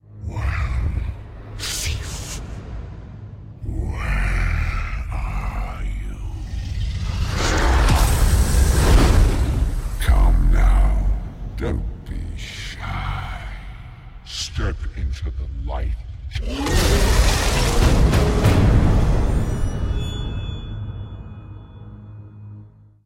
Smaug’s voice as heard in The Hobbit: The Desolation of Smaug trailer